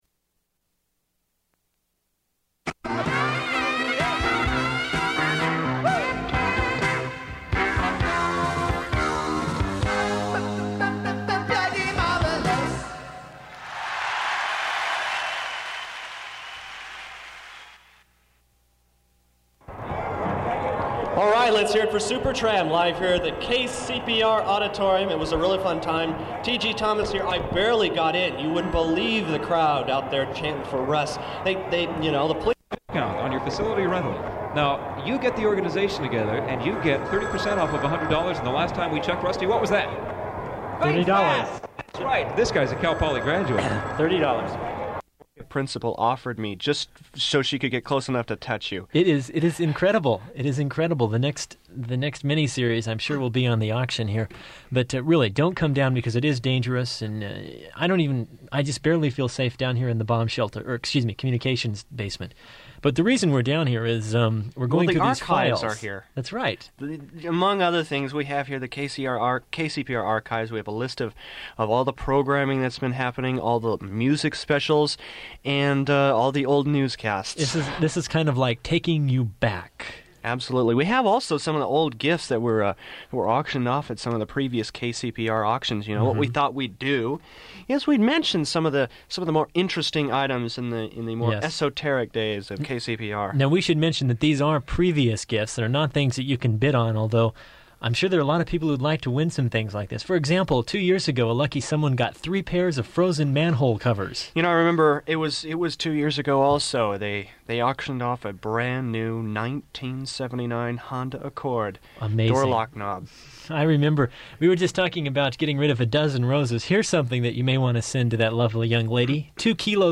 Beginning of recording, frequent dropouts
Bidding continues with banter from auction board as well as descriptions of items
Form of original Open reel audiotape